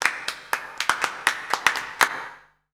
Index of /90_sSampleCDs/Voices_Of_Africa/VariousPhrases&Chants
22_Clapping2.WAV